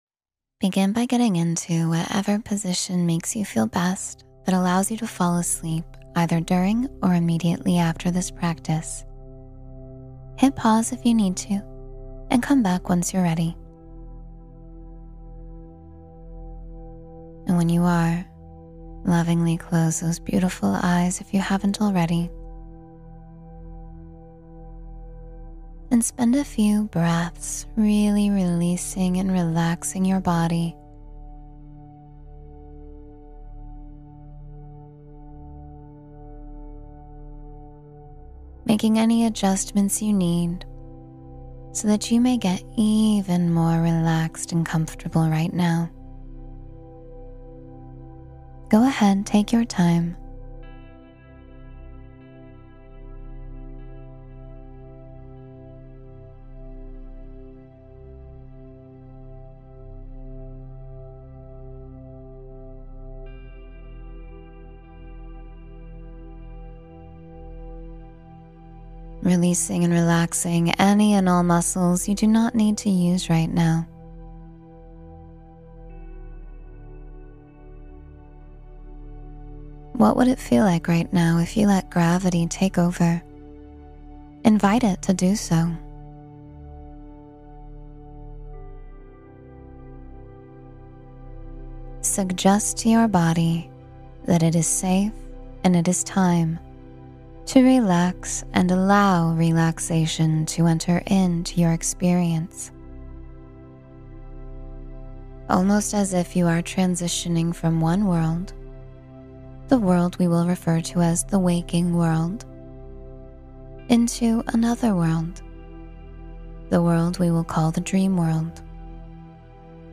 Expand Your Mind in 10 Minutes — Meditation for Clarity and Personal Growth